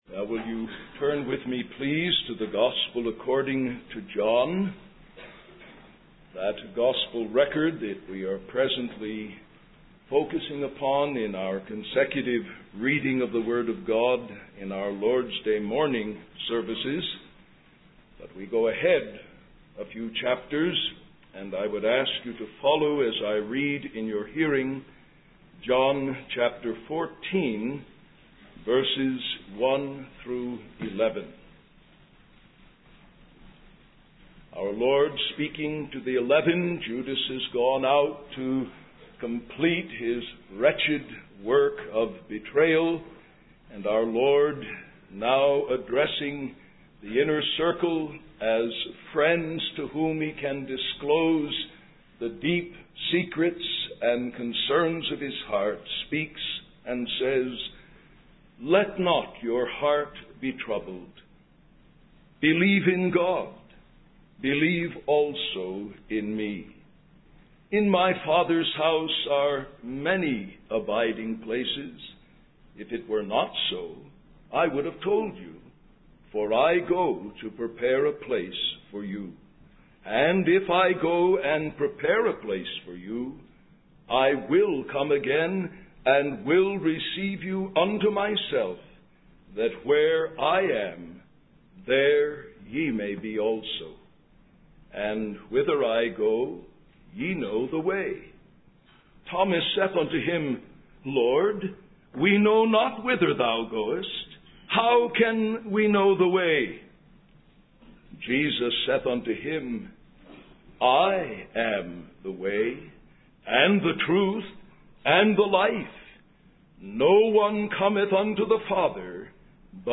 In this sermon, the preacher emphasizes the importance of Jesus Christ as the Savior for all mankind. He describes Jesus as the perfect solution to every sinner's need.